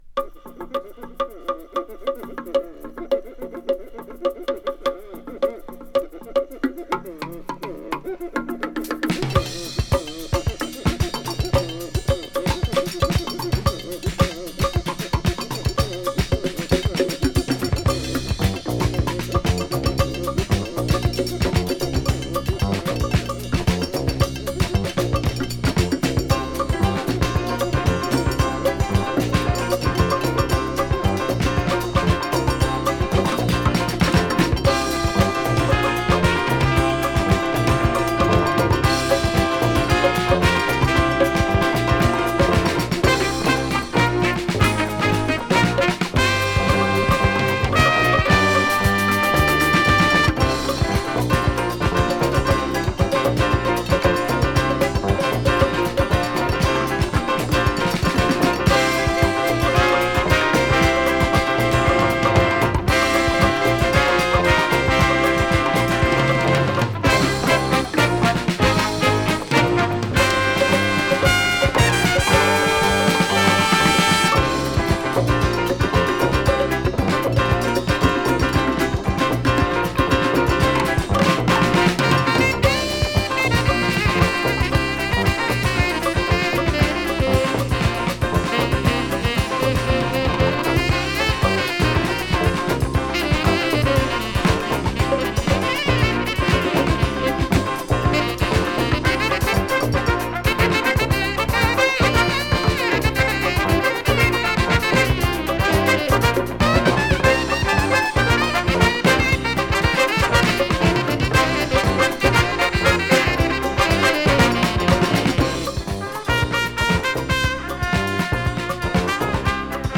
Private Press Jazz Funk from Arizona！
ラテン・ジャズファンク
【JAZZ FUNK】【FUSION】